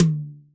• High Tom Drum Single Hit D Key 08.wav
Royality free tom sample tuned to the D note. Loudest frequency: 1690Hz
high-tom-drum-single-hit-d-key-08-A6J.wav